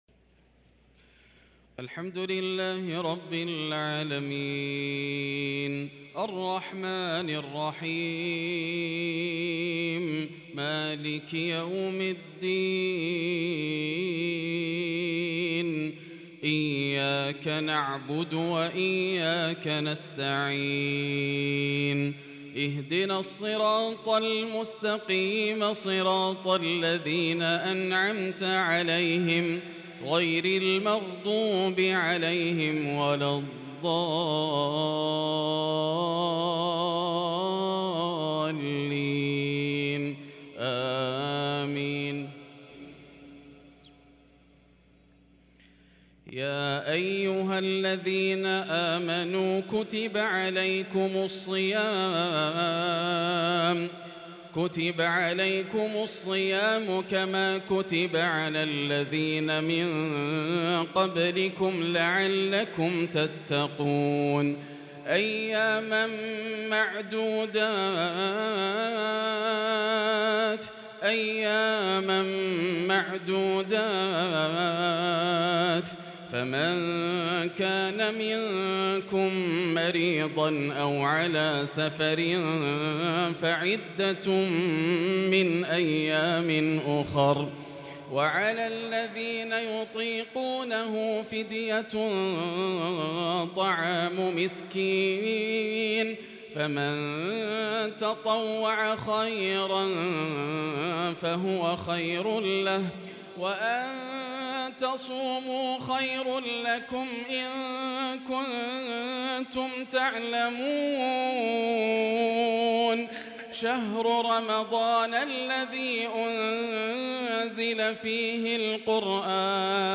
مغرب الجمعة 29 شعبان 1443هـ من سورة البقرة | Maghrib prayer from surah Al-Baqarah 1-4-2022 > 1443 🕋 > الفروض - تلاوات الحرمين